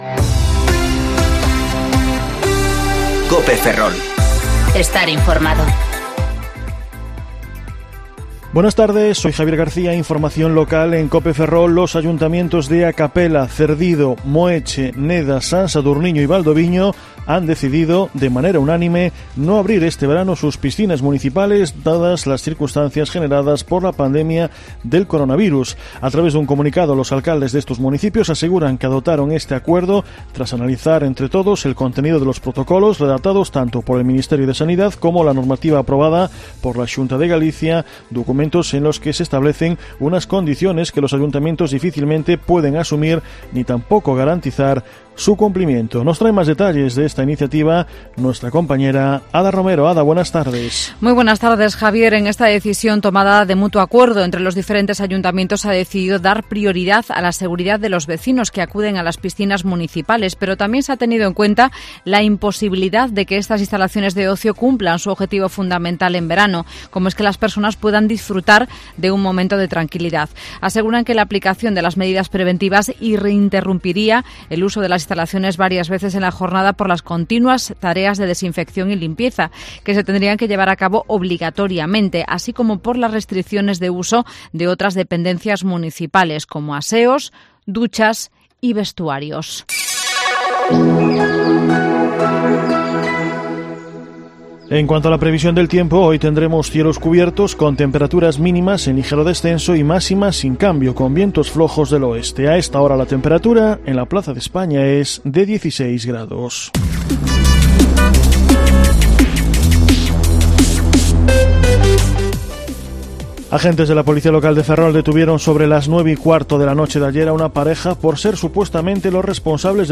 Informativo Mediodía COPE Ferrol 17/06/2020 ( De 14,20 a 14,30 horas)